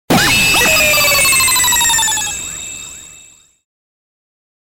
• 昇格演出中/図柄昇格成功音